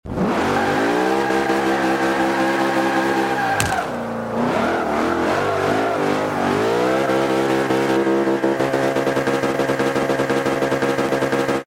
Index of /server/sound/vehicles/lwcars/merc_slk55
rev.wav